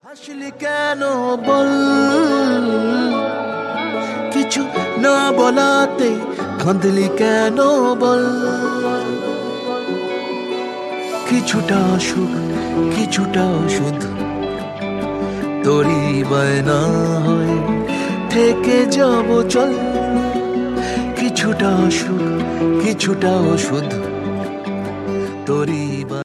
Bengali Romantic